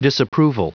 Prononciation du mot disapproval en anglais (fichier audio)
Prononciation du mot : disapproval